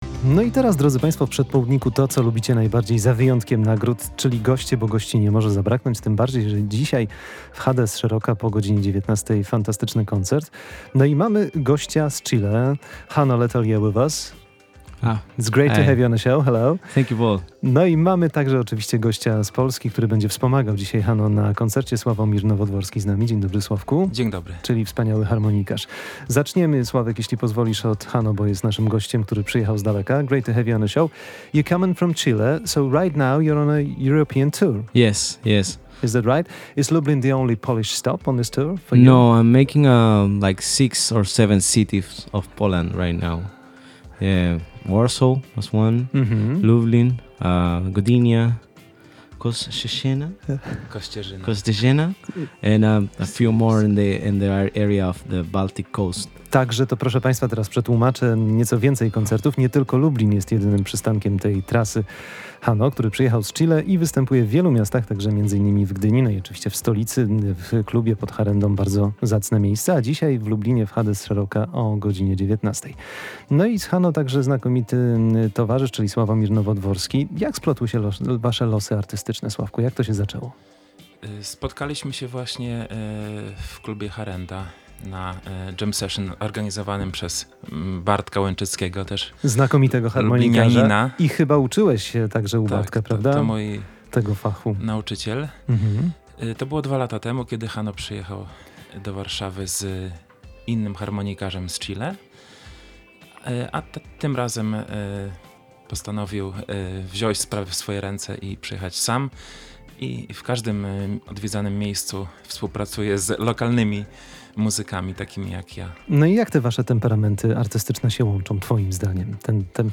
gitarzysta i kompozytor z Chile
znakomity harmonijkarz.